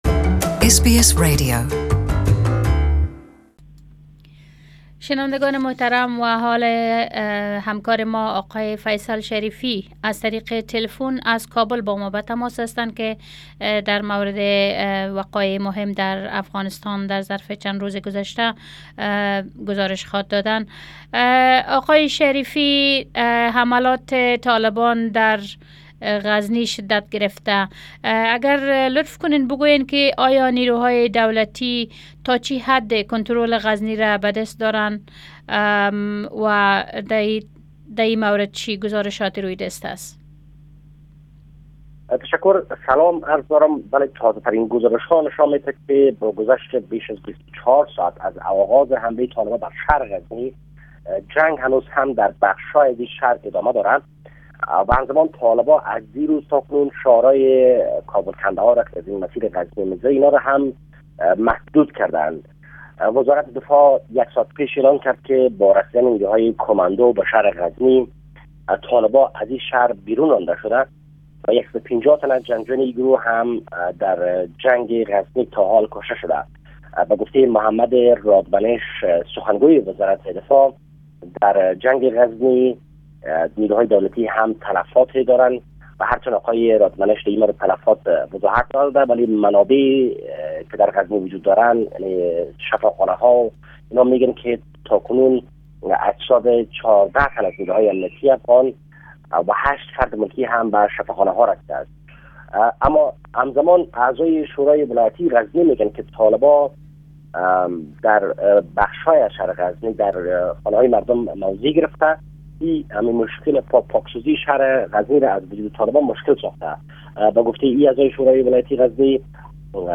latest Report from Kabul